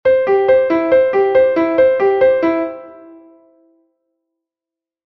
3/4: tres grupos de semicorcheas iguais